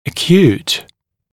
[ə’kjuːt][э’кйу:т]остроконечный, острый (в т.ч. о заболевании)